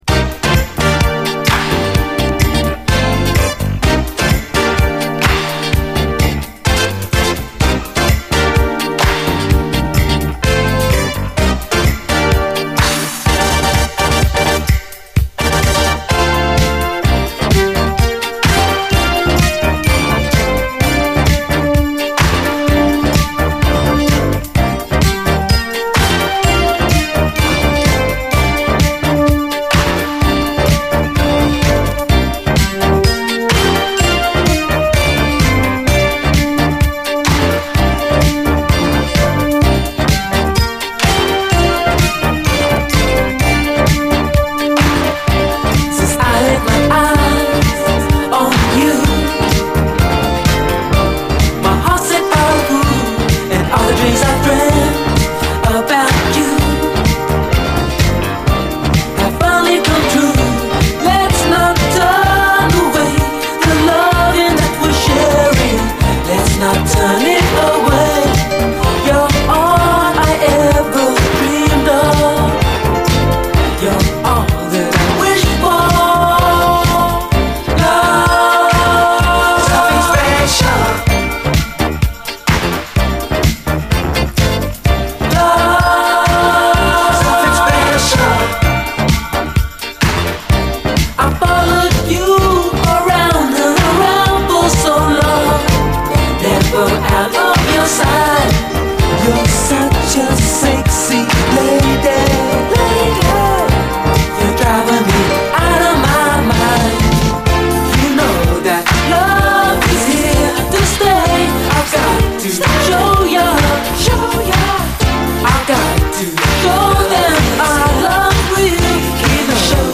SOUL, 70's～ SOUL, DISCO
イントロから爽快なブリット・ファンク・サウンドが炸裂する、最高レアUKブギー！